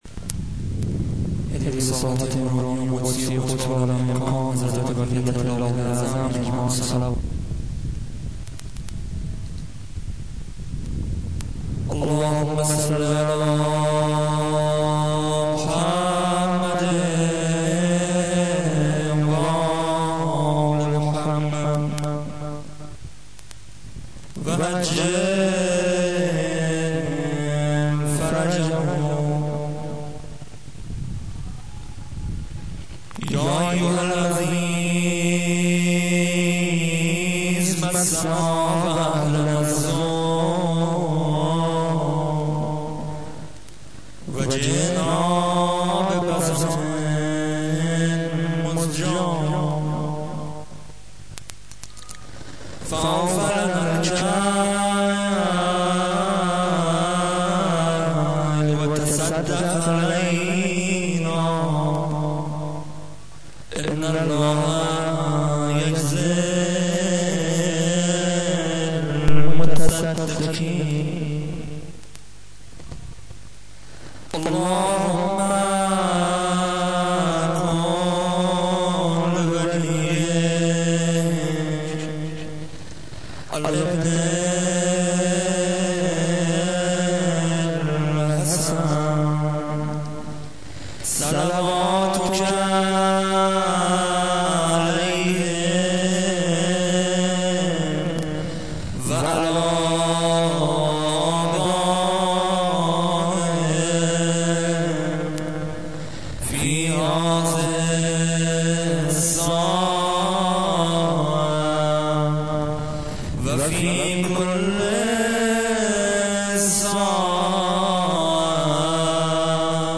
زیارت عاشورای گلزار شهدای زنگی آباد